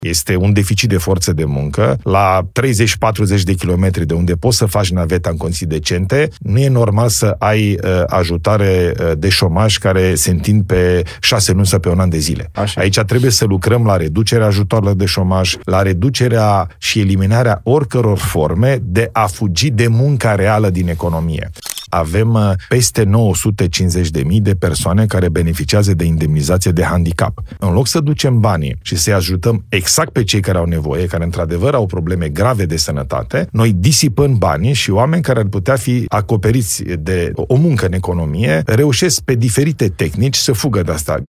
Într-un interviu pentru podcastul ApropoTv, Ilie Bolojan spune că țara a plătit anul acesta șase miliarde de lei pentru concediile medicale luate de români.